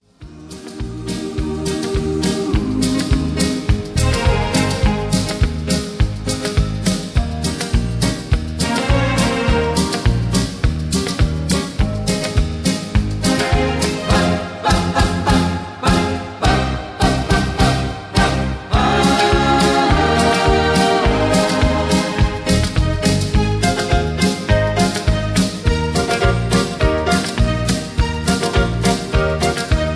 backing tracks , karaoke